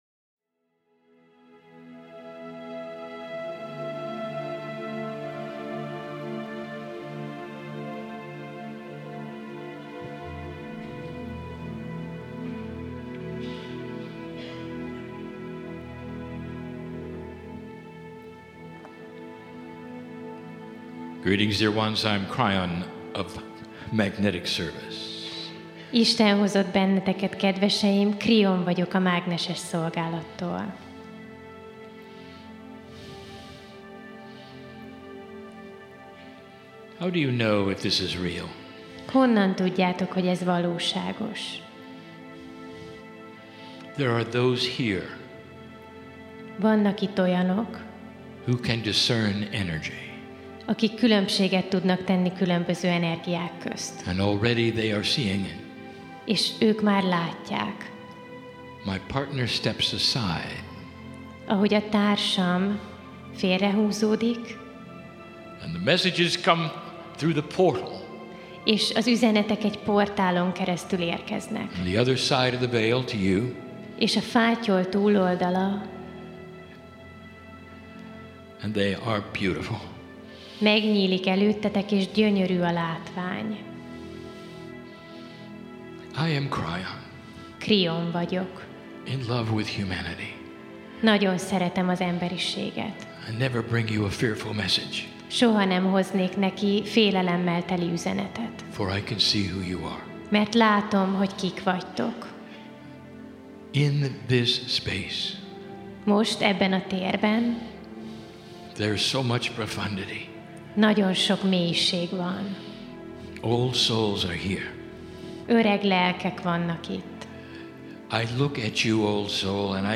Saturday Mini channelling